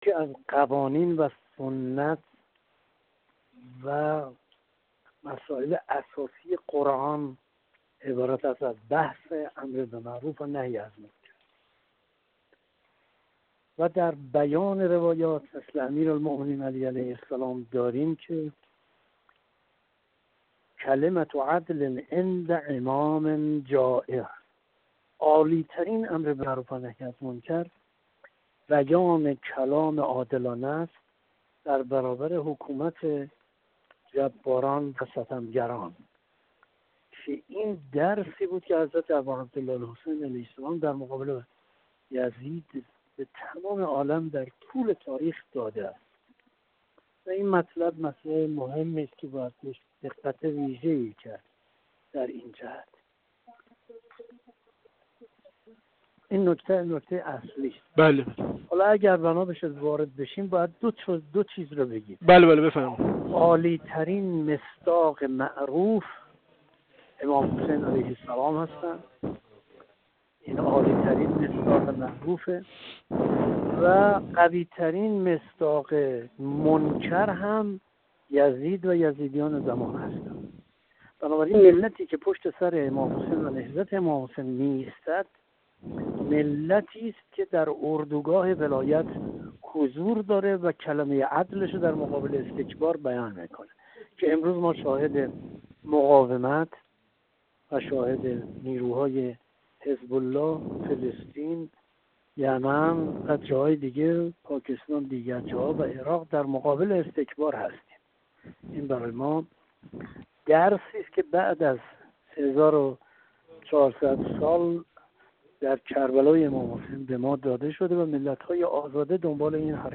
حجت‌الاسلام و المسلمین احمد سالک، عضو جامعه روحانیت مبارز، در گفت‌وگو با ایکنا درباره نقش و جایگاه فریضه امر به معروف و نهی از منکر حاکمان در حرکت امام حسین(ع) گفت: یکی از قوانین و سنت‌‌‌ها و مسائل اساسی قرآن، امر به معروف و نهی از منکر است.